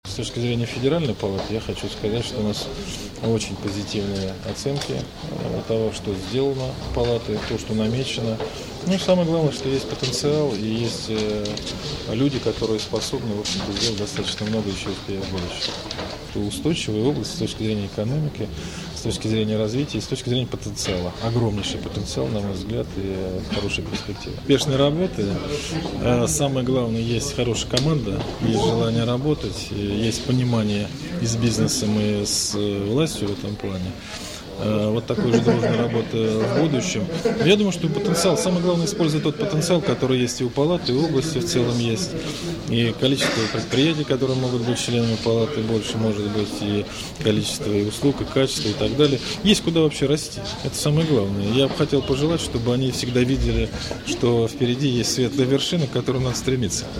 Сергей Катырин поздравляет ВТПП с юбилеем